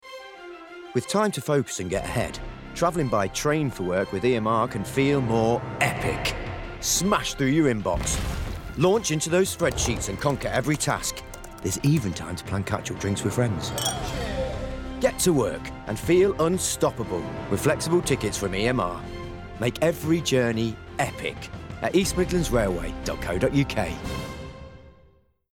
New new commercial showreel!
Neutral/RP, Warm/Natural/Versatile